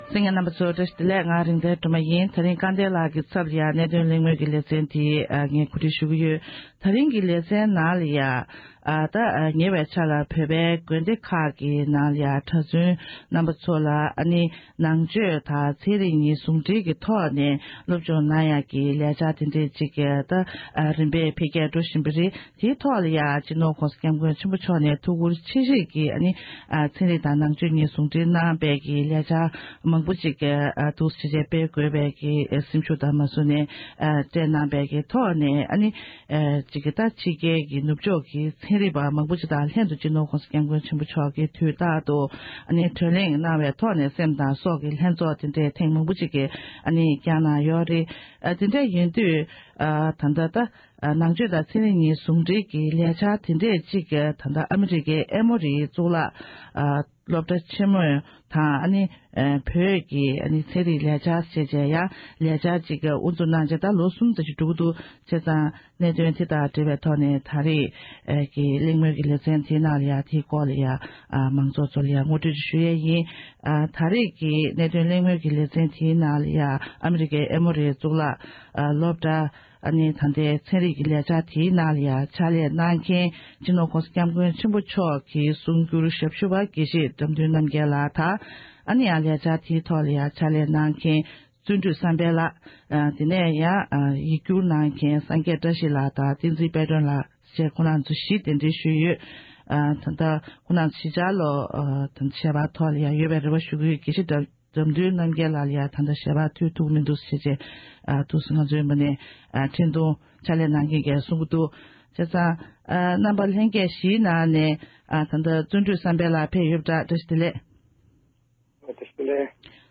༄༅༎ཐེངས་འདིའི་གནད་དོན་གླེང་མོལ་གྱི་ལེ་ཚན་ནང་དུ་ཨ་མེ་རི་ཀའི་ཨེ་མོ་རི་ཆེས་མཐོའི་གཙུག་ལག་སློབ་གཉེར་ཁང་འབྲས་སྤུངས་བློ་གསལ་གླིང་དབར་ཚན་རིག་དང་ནང་དོན་རིག་པའི་དབར་གྱི་བགྲོ་གླེང་ཡོང་བའི་གོ་སྐབས་བསྐྲུན་ཡོད་པའི་གནས་ཚུལ་སྐོར་འབྲེལ་ཡོད་མི་སྣ་ཁག་ཅིག་གི་ལྷན་དུ་